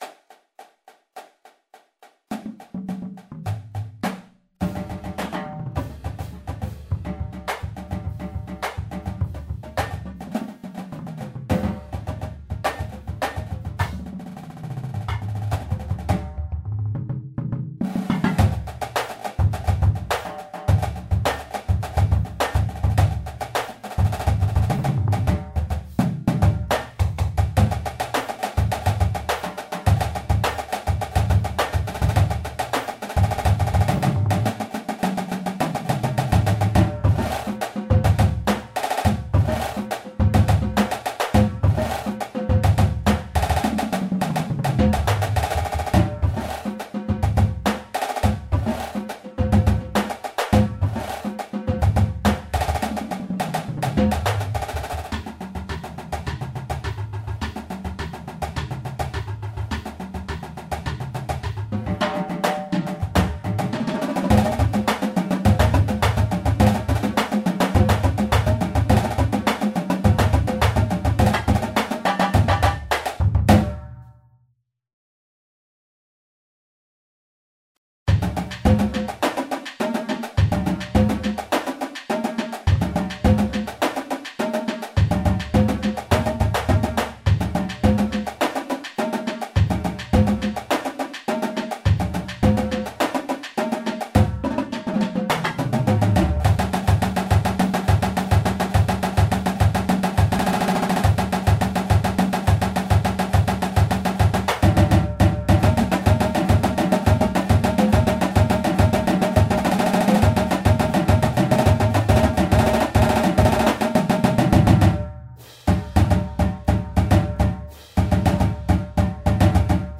Drumline Music